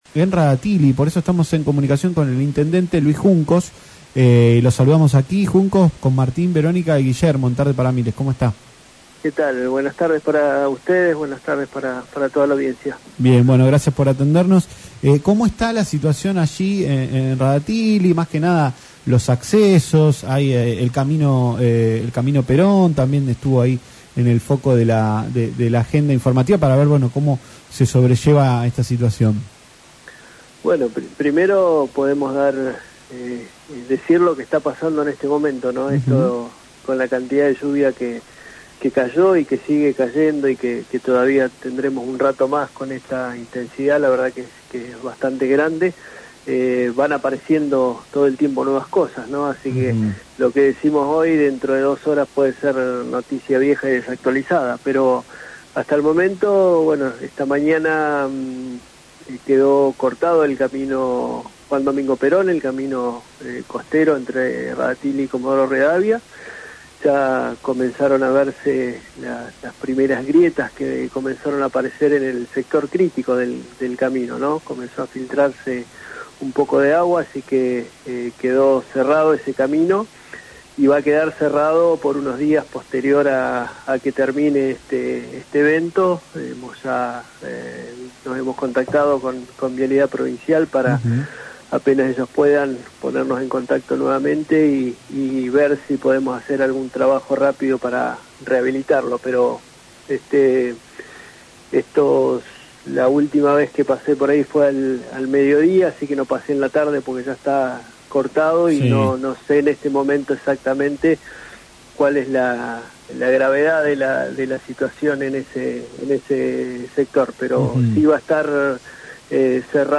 El intendente de Rada Tilly, Luis Juncos, habló con Tarde Para Miles por LaCienPuntoUno y se refirió a cómo afectó el temporal de lluvia a la localidad.